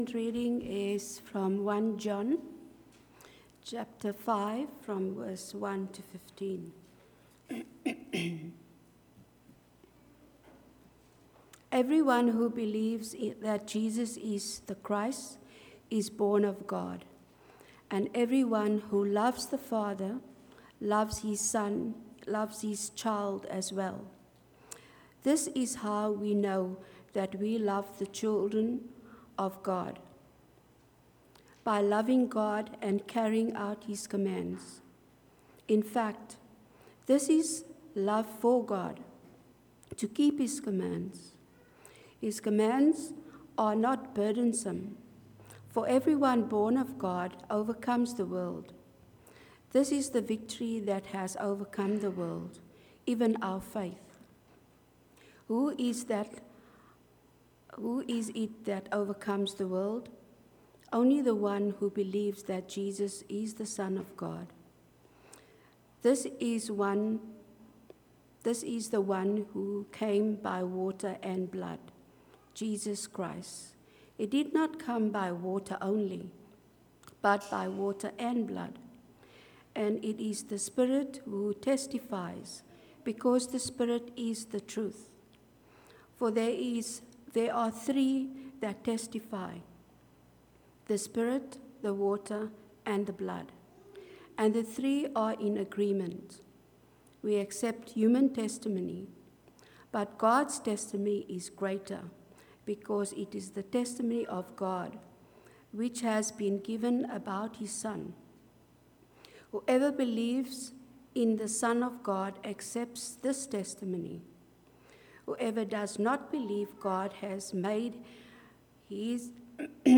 Service Type: Rosemeadow AM